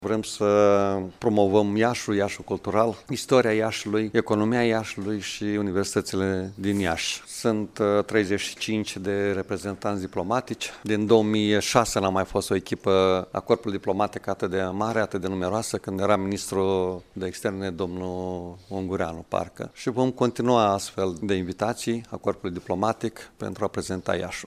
Preşedintele Consiliului Judeţean, Maricel Popa, a declarat că printre țările reprezentate sunt Argentina, Brazilia, Chile, Danemarca, Ucraina, Irak şi Iran.